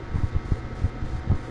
machinerydrone01.ogg